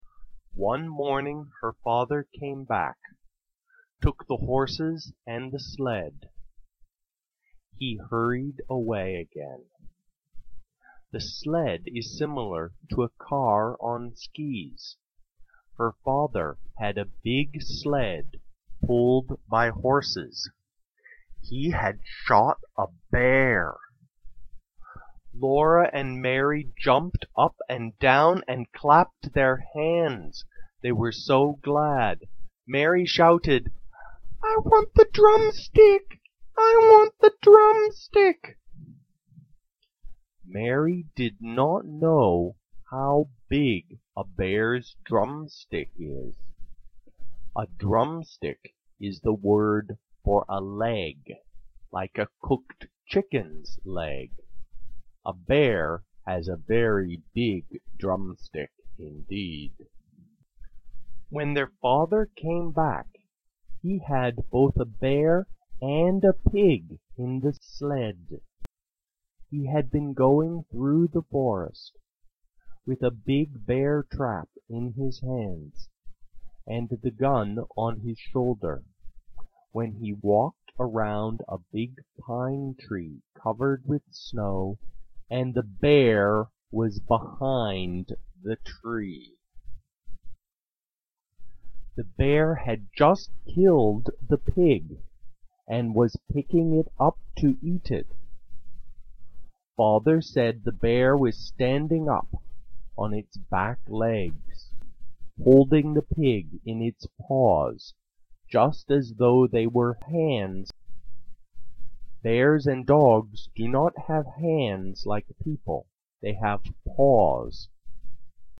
This summer I will read you the second chapter. It is the story of a little girl growing up in America in the old days before it was a developed country.
A native English speaker who has been teaching practical English in Hong Kong for more than a decade.